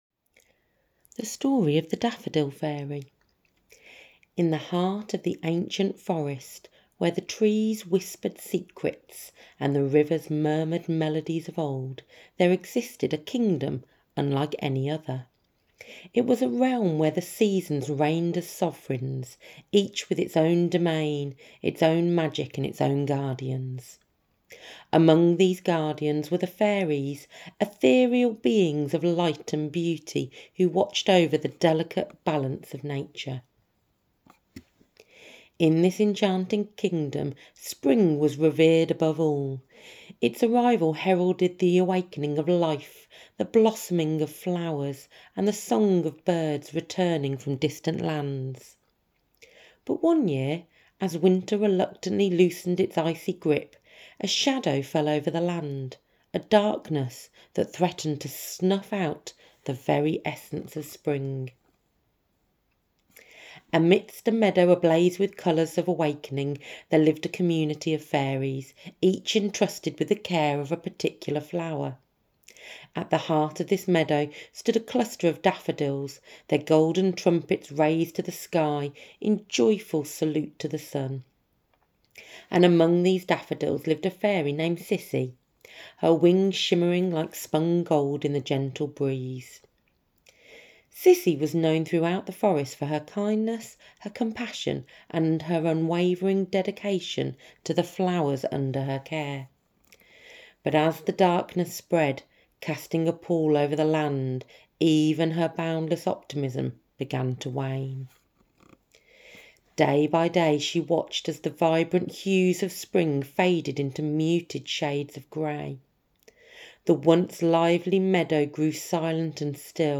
Audio story of The Daffodil Fairy: Saving Spring